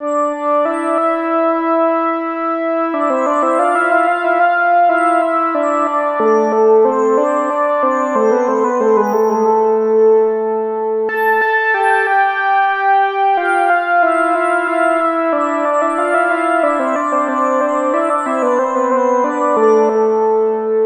Synth 02.wav